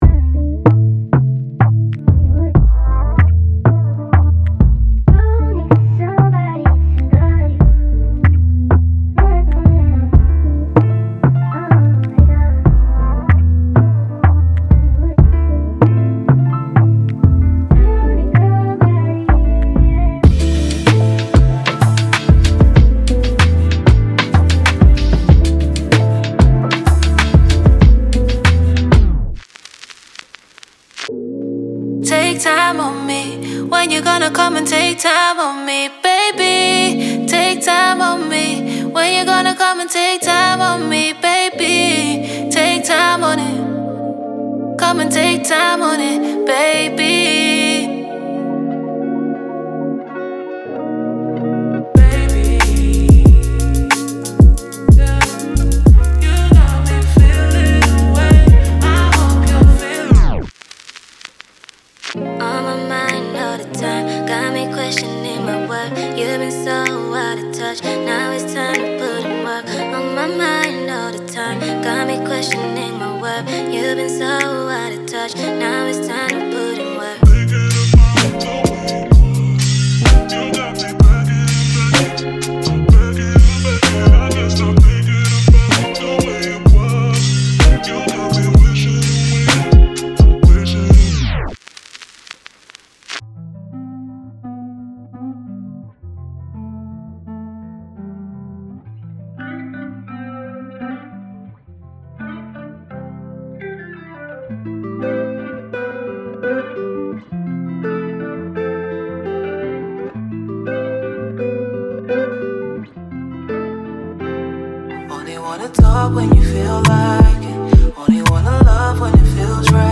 它将 R&B 的丝滑感与流行音乐的吸引力，以及专业的乐器创意完美融合。
这些循环非常适合为你的音乐添加丝滑的质感、音乐天赋和动态人声，它们将立即激发并增强你的创作过程。